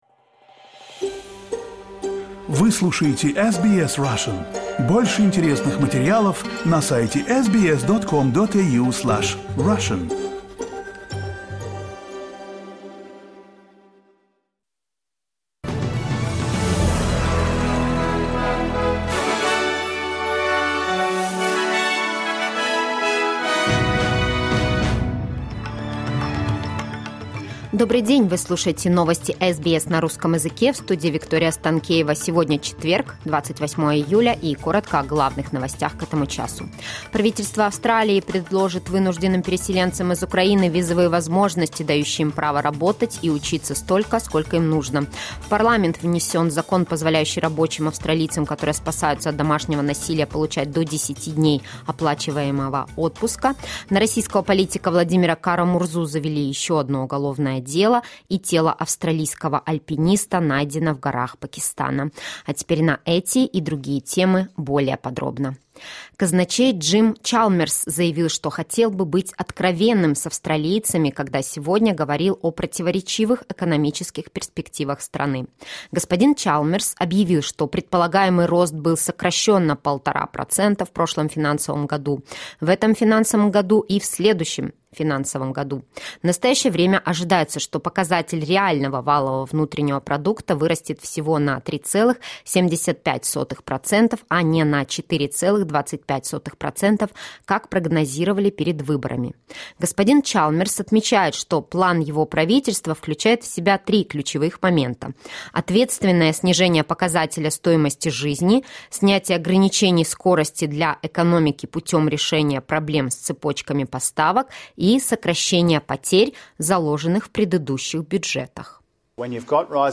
SBS News in Russian - 28.07.22